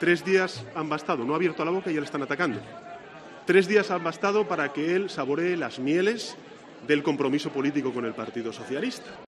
Sánchez ha presentado a Hernández en el acto de lanzamiento de su candidatura a las primarias, ante unas mil personas, en el Teatro de La Latina, entre ellas la ministra de Industria, Reyes Montiel, y la cúpula del PSOE con la vicesecretaria general, Adriana Lastra, y el secretario de Organización, José Luis Ábalos, al frente.